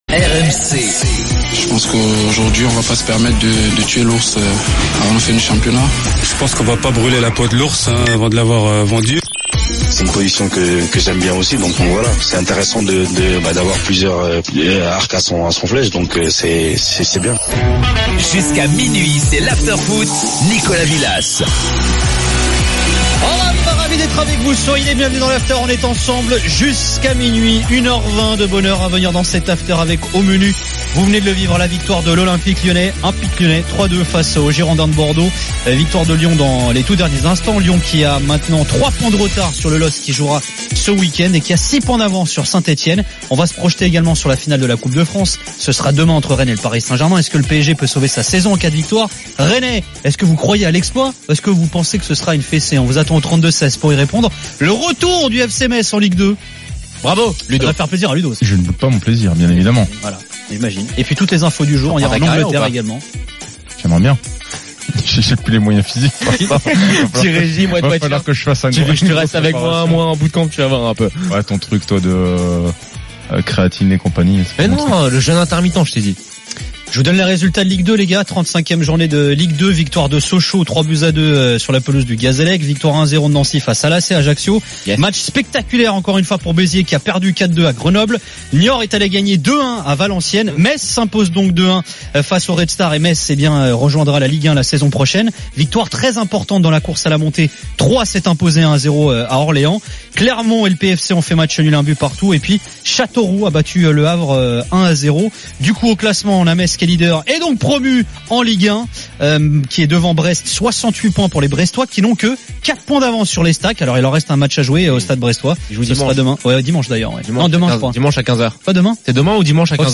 Chaque jour, écoutez le Best-of de l'Afterfoot, sur RMC la radio du Sport.